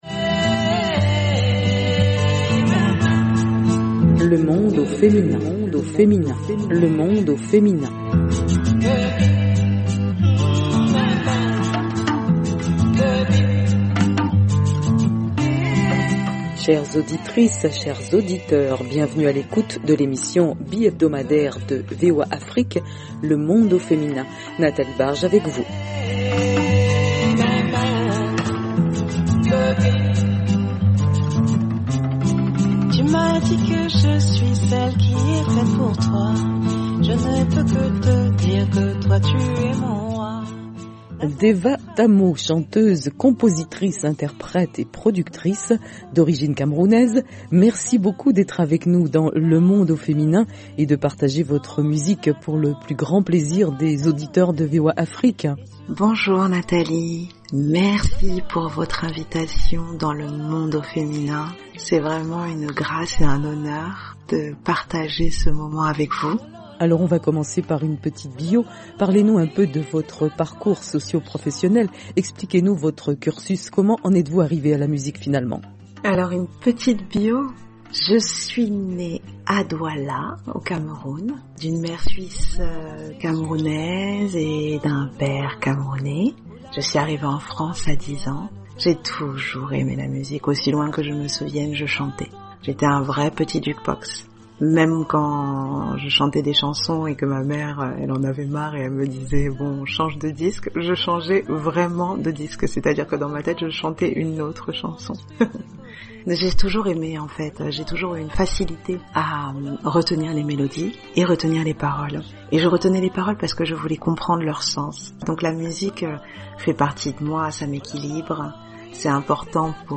Le Monde au Féminin : Entretien musical